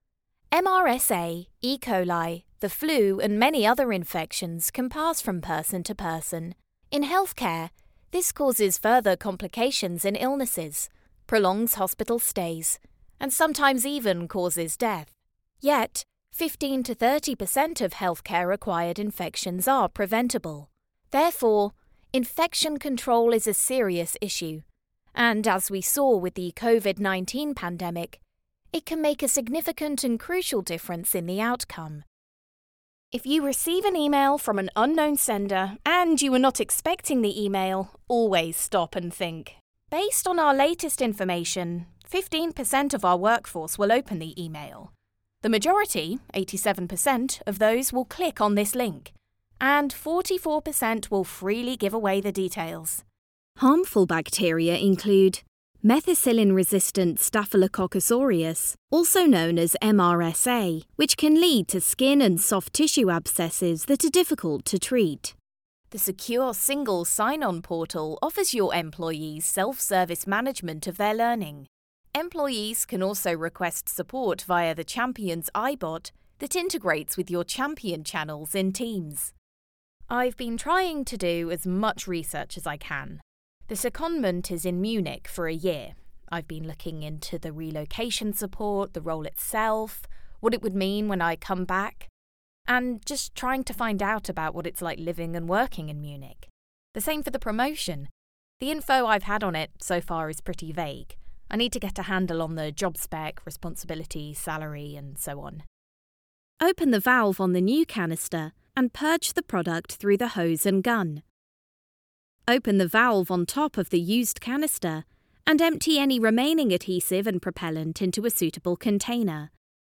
Clear, friendly and engaging British Female voice
Sprechprobe: eLearning (Muttersprache):
Highly experienced, British female voiceover artist with broadcast-quality home studio based in London, UK.